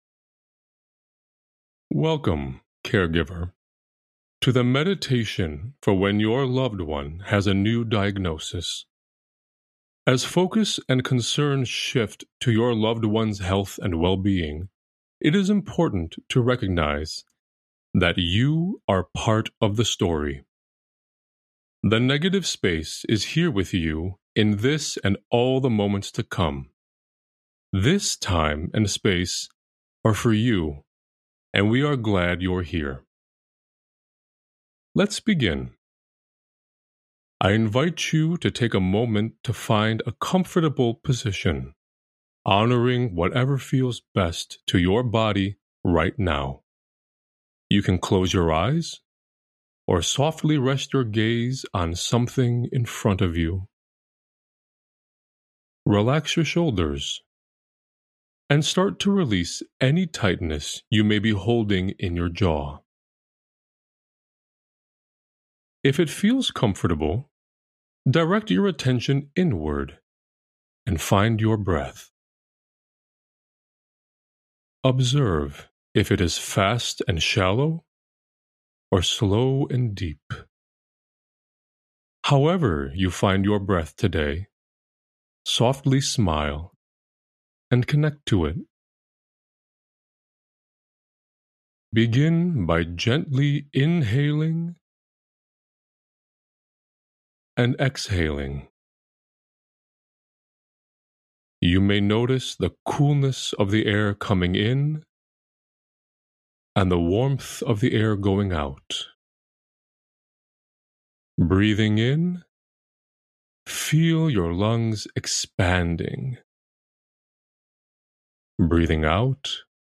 Guided Meditation for a new diagnosis